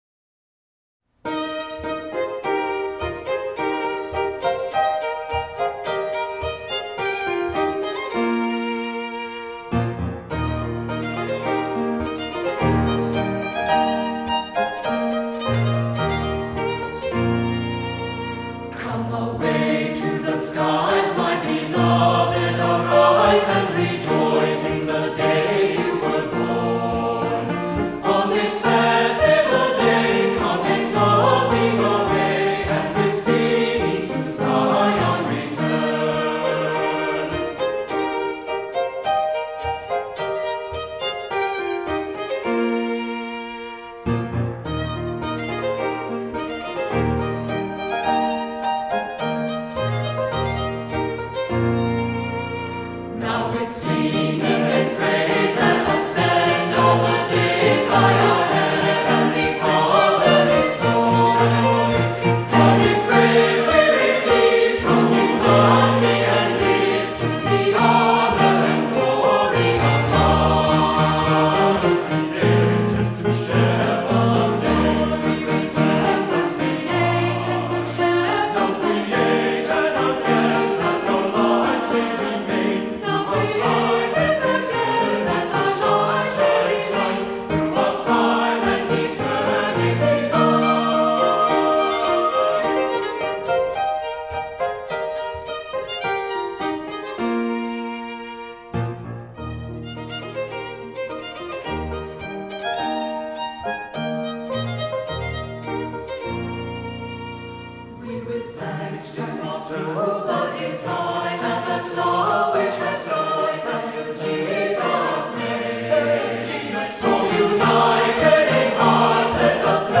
Voicing: SATB, assembly